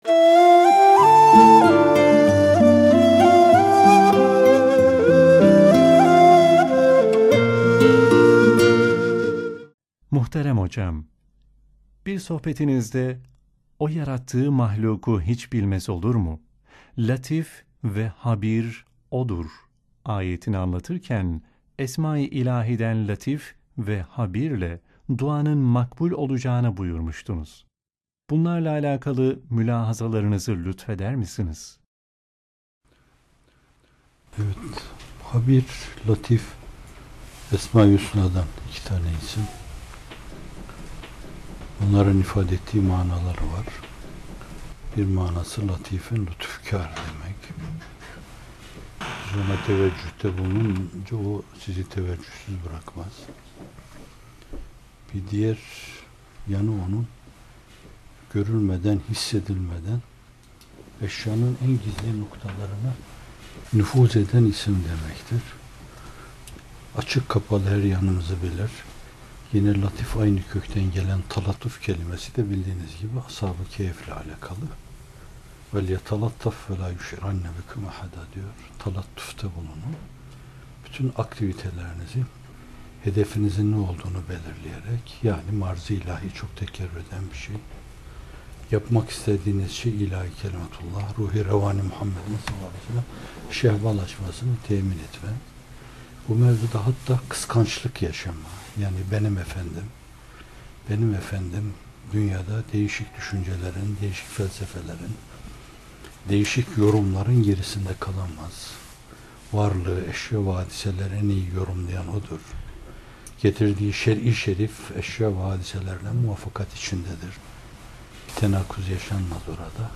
Muhterem Fethullah Gülen Hocaefendi bu videoda Mülk Suresi 14. ayet-i kerimesinin tefsirini yapıyor: اَلَا يَعْلَمُ مَنْ خَلَقَۜ وَهُوَ اللَّط۪يفُ الْخَب۪يرُ۟ O yarattığı mahlûkunu hiç bilmez olur mu?